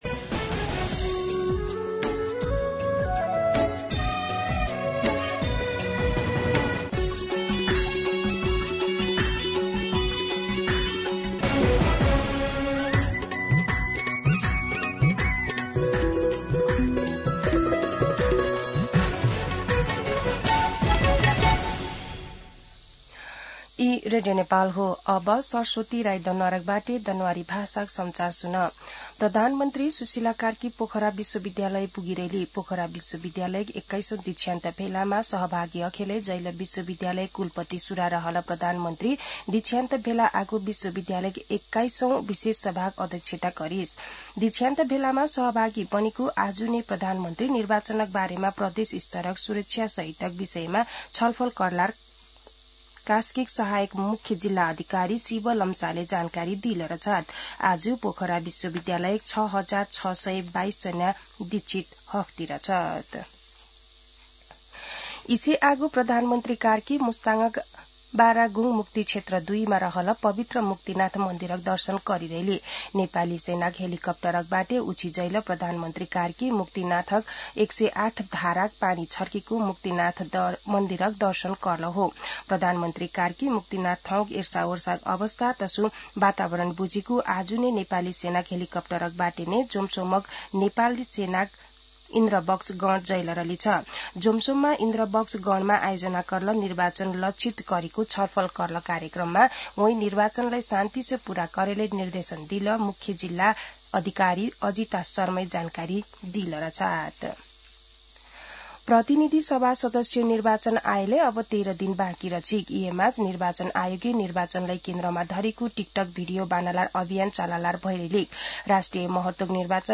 दनुवार भाषामा समाचार : ८ फागुन , २०८२
Danuwar-News-11-8.mp3